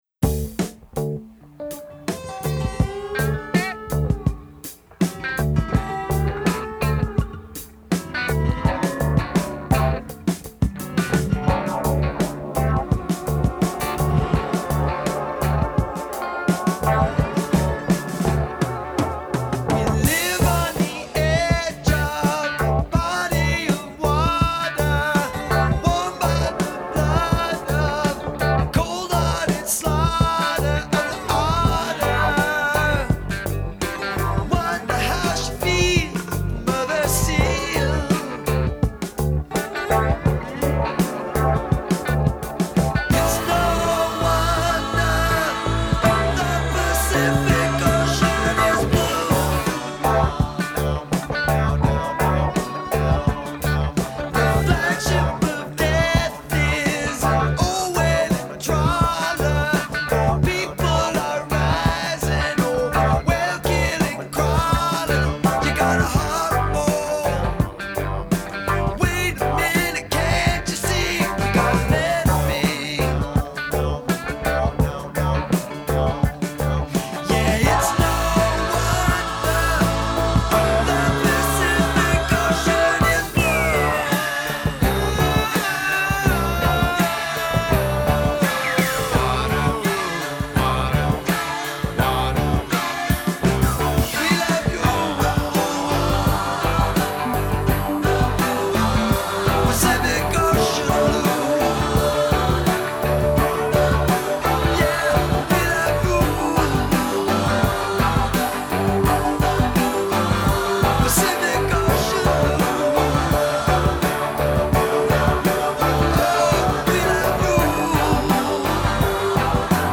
more heartfelt and soulful
but he’s earnest without being maudlin.